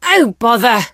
rosa_hit_01.ogg